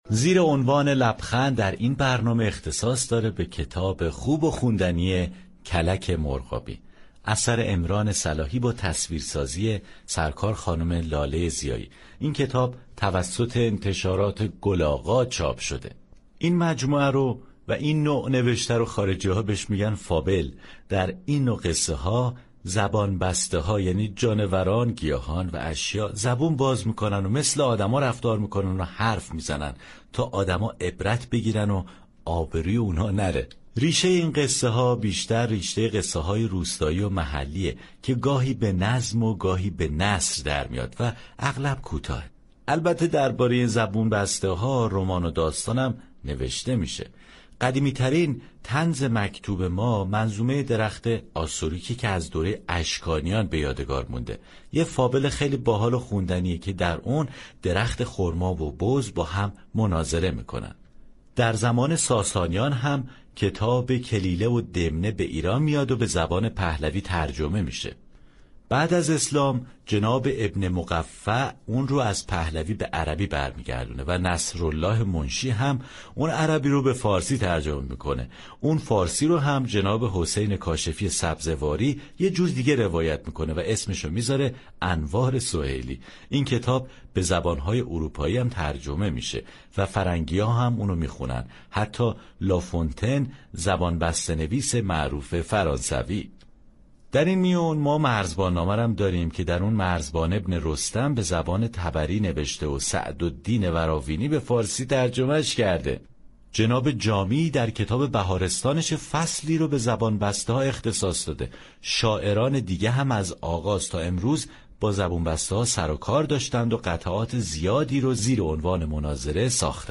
اشعار طنز «عمران صلاحی» را در كتاب «كلك مرغابی» می‌خوانیم.